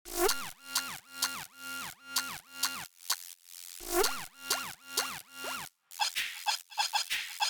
FUEL_-4-Fuel_128-Fx_Full_2.mp3